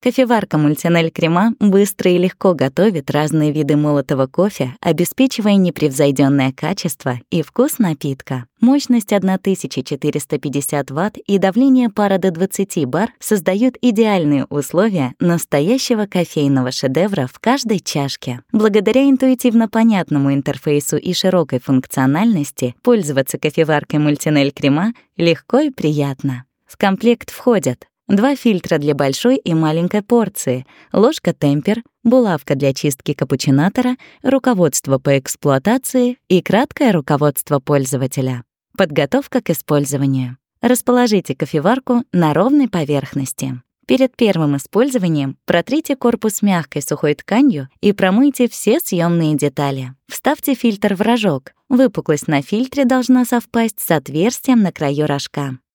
Commercieel, Jong, Natuurlijk, Vriendelijk, Zakelijk
Explainer
- native russian speaker without region accent